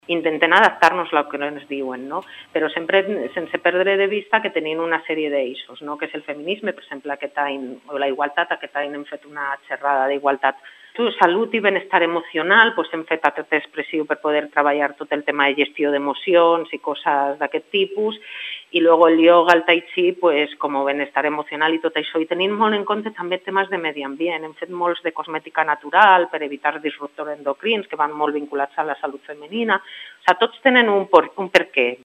ens ha explicat als micròfons d’aquesta casa algunes de les activitats d’aquesta edició.